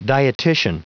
Prononciation du mot dietician en anglais (fichier audio)
Prononciation du mot : dietician